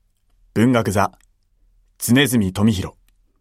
ボイスサンプルはこちら↓